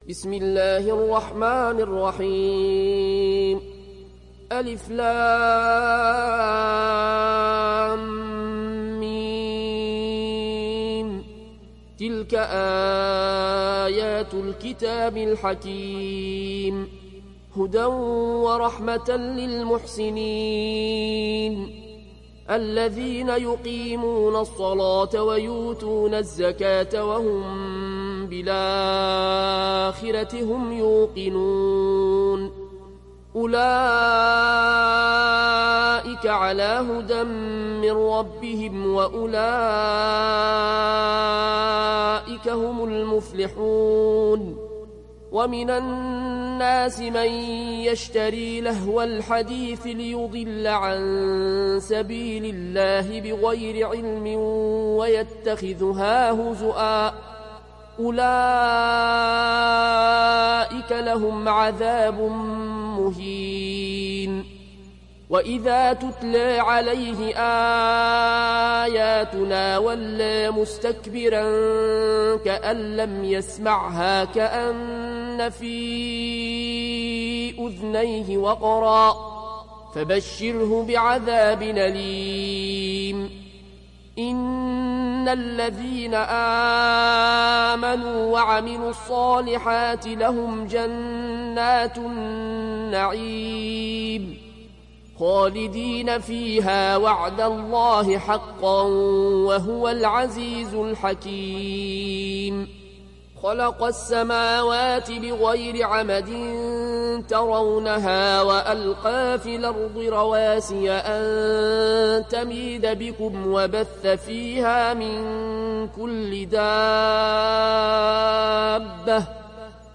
Riwayat Warsh
Riwayat Warsh dari Nafi